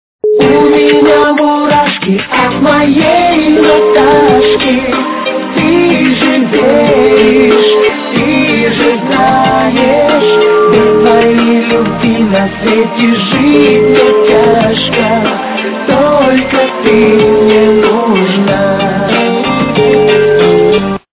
русская эстрада
припев качество понижено и присутствуют гудки.